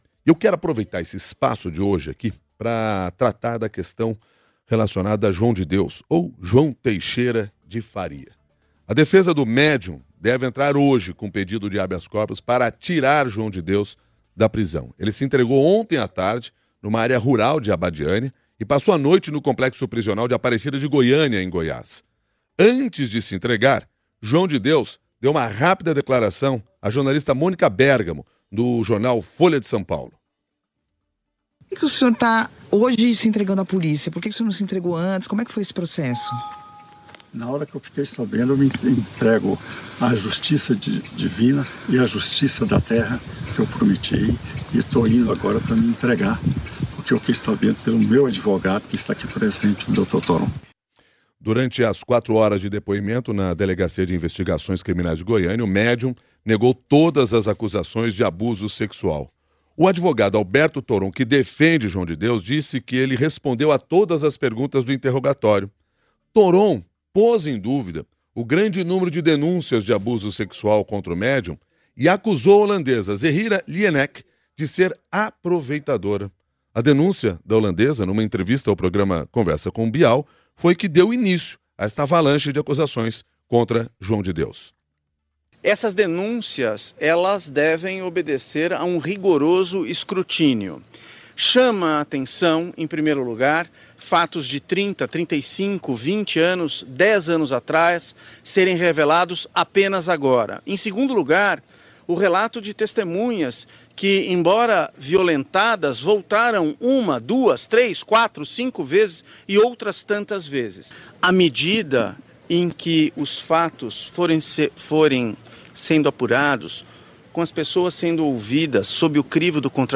A promotora de Justiça Gabriela Manssur, integrante da força-tarefa que está recebendo denúncias do caso João de Deus, afirmou em entrevista à CBN nesta segunda-feira (17), que depoimentos de algumas mulheres dão a entender que havia um esquema de pessoas que sabiam o que acontecia dentro da sala.